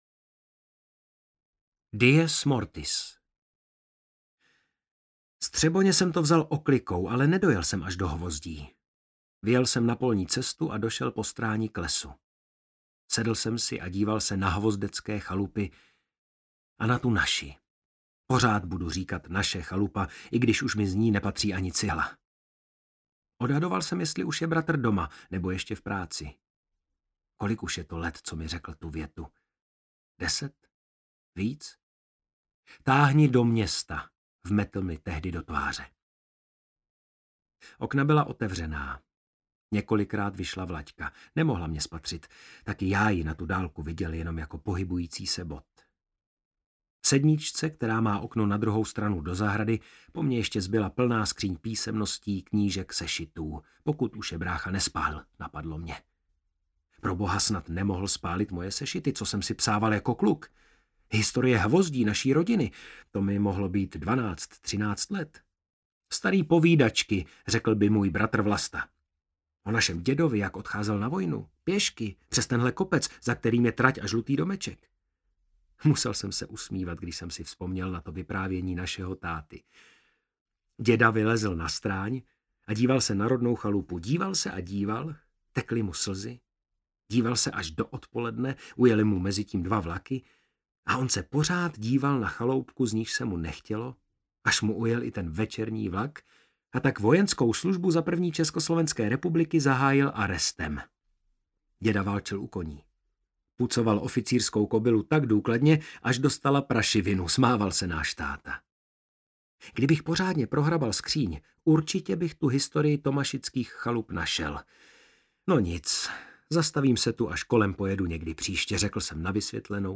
AudioKniha ke stažení, 20 x mp3, délka 5 hod. 57 min., velikost 327,0 MB, česky